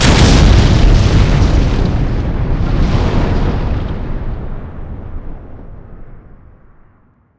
explode3.wav